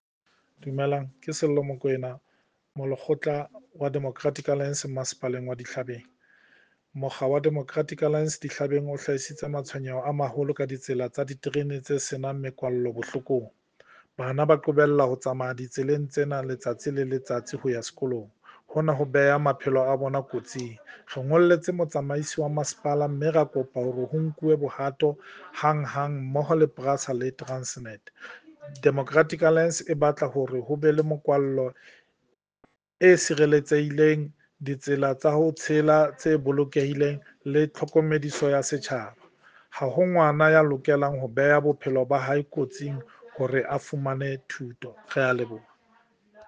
Sesotho soundbites by Cllr Sello Mokoena and